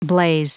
Prononciation du mot blaze en anglais (fichier audio)
Prononciation du mot : blaze